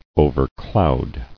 [o·ver·cloud]